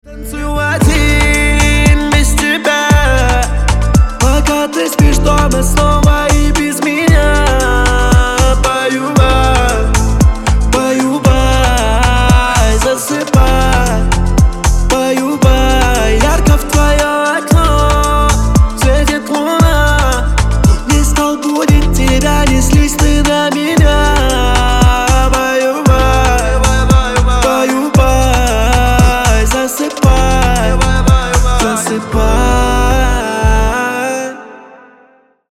Спокойный рингтон на жену или девушку